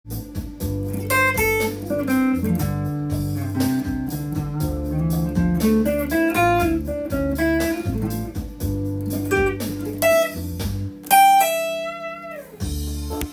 定番【プチスィープ奏法フレーズ集】フュージョンおしゃれギター
Dm7のコード上で使えるフレーズをtab譜にしてみました。
２～３本ほどの弦をさらっと弾くフレーズになります。